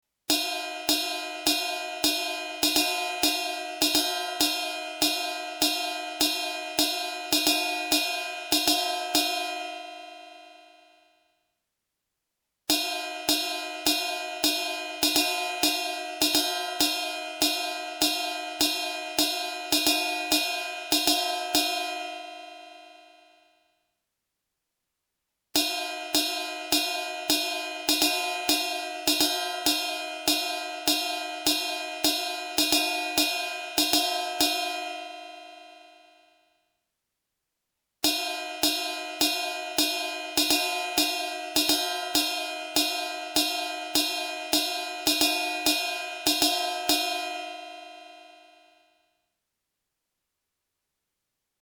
The sound samples are once again taken from the trusty Roland R-8.
Ride 3 (center bell cup): 280Hz
Played 4×20 (rhythmic riff)
Bells (center of the ride) are an odd ball since they usually have an equal blend of dominant frequencies.
fk4_Ride_3_Center_Bell_Cup_280Hz.mp3